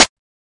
Snares